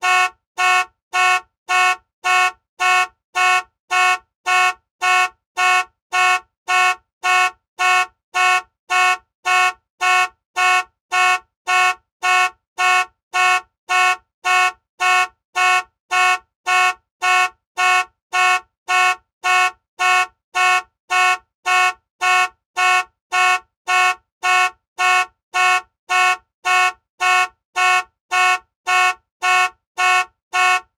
Car Alarm Horn Sound
transport
Car Alarm Horn